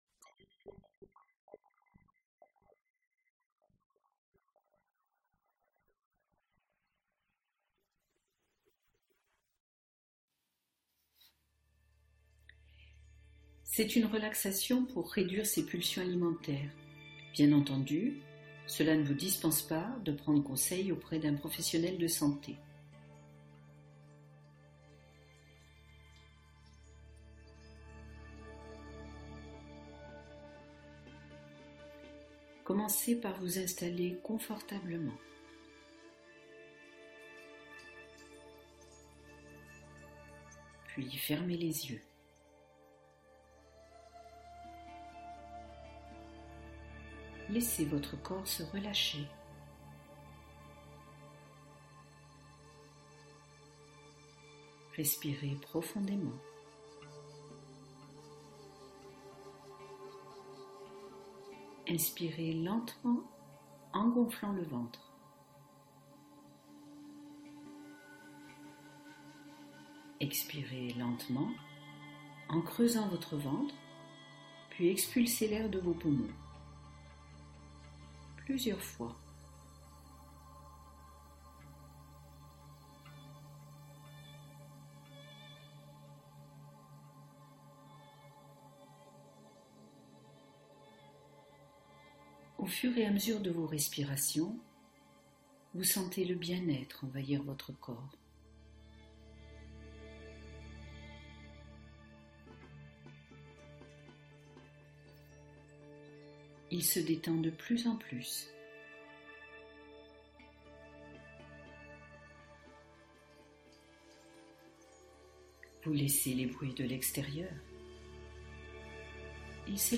Soutien Minceur : Hypnose pour stabiliser ses habitudes alimentaires